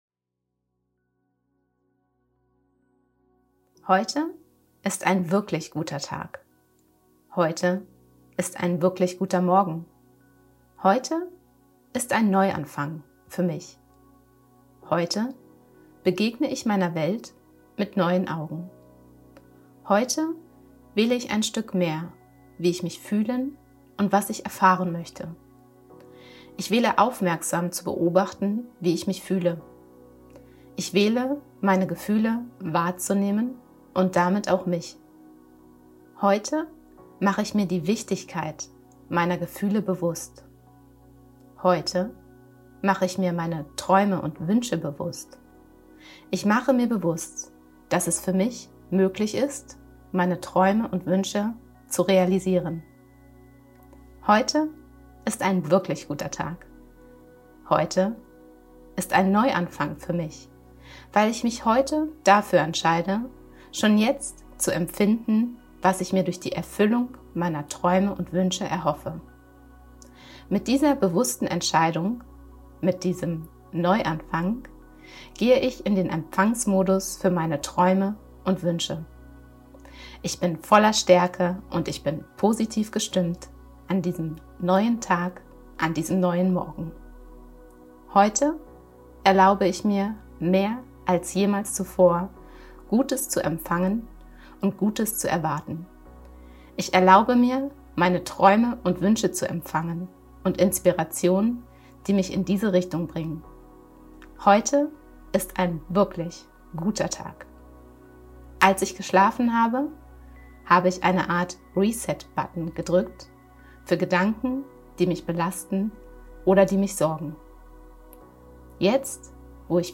Pep-Talk-fuer-den-Morgen_mit-Musik.mp3